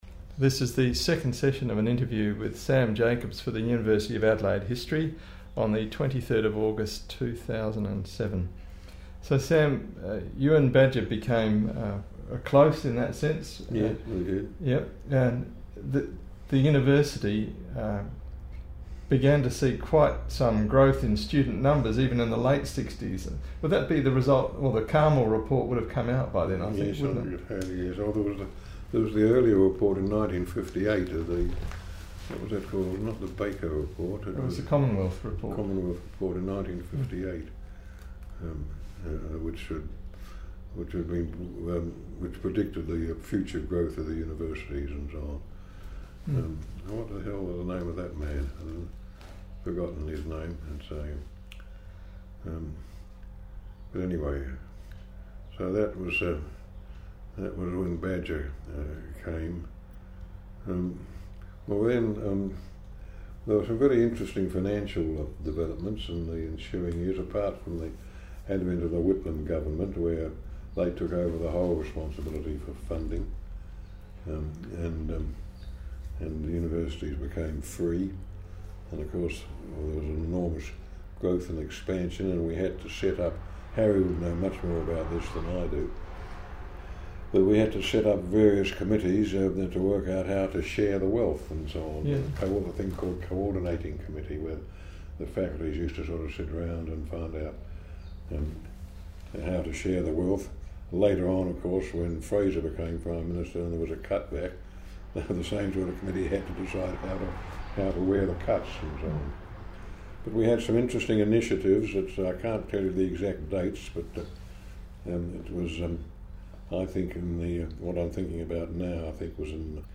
Interview with The Honourable Samuel Jacobs - Chief Justice of South Australia